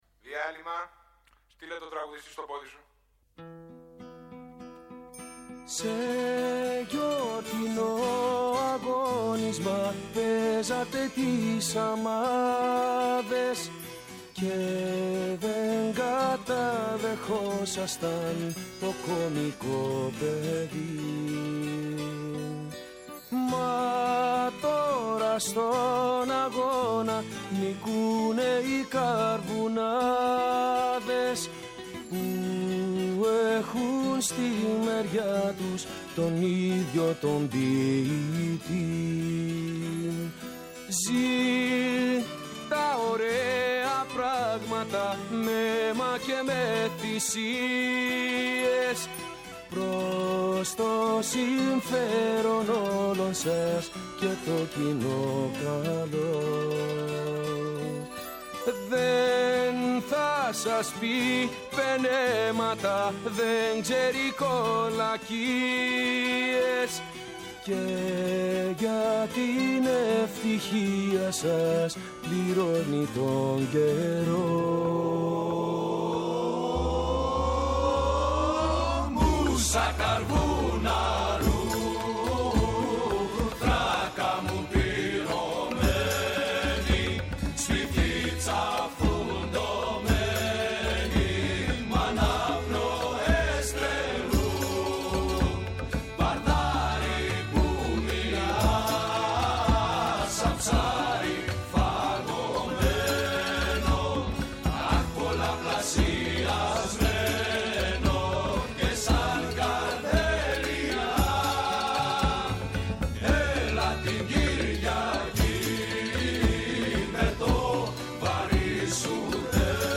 Καλεσμένη σήμερα στο studio